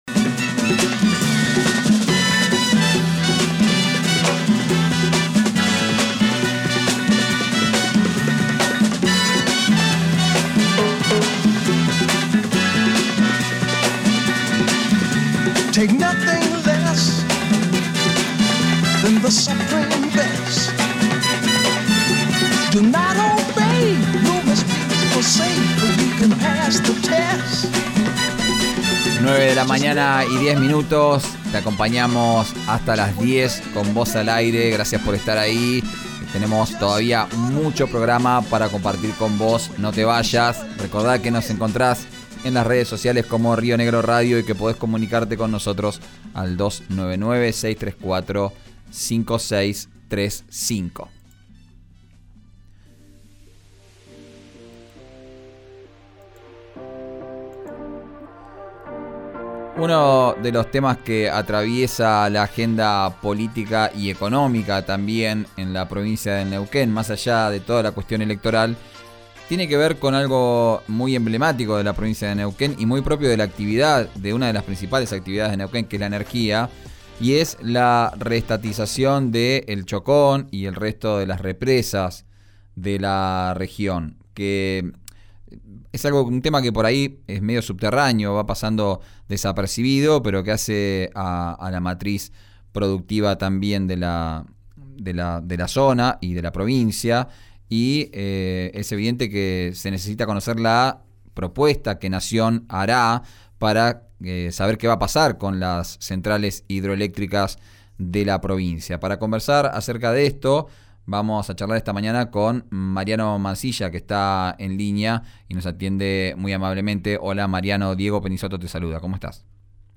En diálogo con RÍO NEGRO RADIO, el diputado por el Frente de Todos – UNE, Mariano Mansilla, contó que pidieron una reunión formal con Royón, «para discutir el tema», y esperan una respuesta para hoy martes.